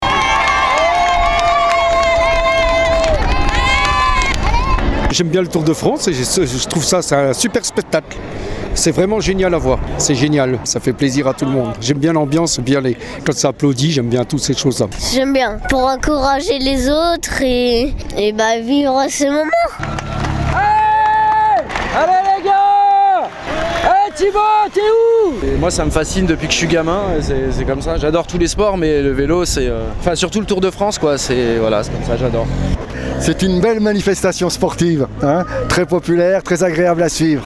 Cela n’aura pas empêché le public d’encourager les coureurs comme il se doit sur le bord des routes. Et il y avait de l’ambiance comme ici au passage des champions à Sallanches
ambiance-tdf-57275.mp3